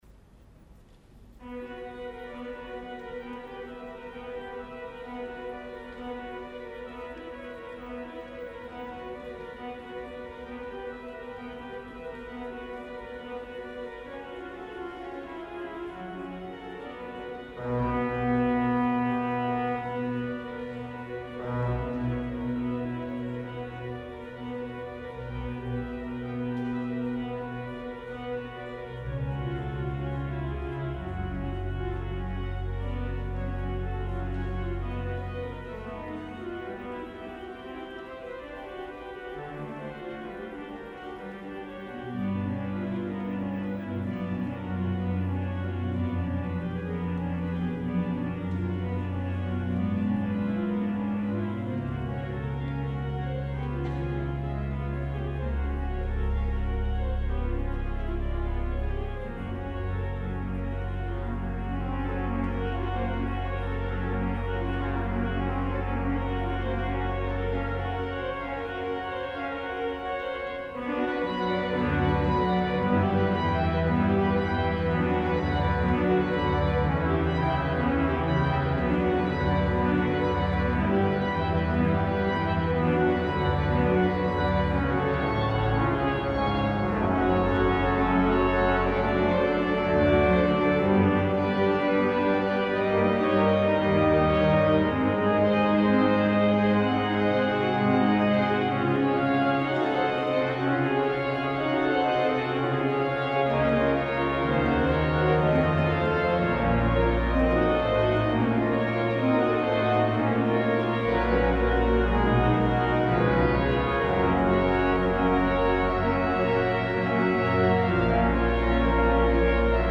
Concert inaugural du 31 mai 2002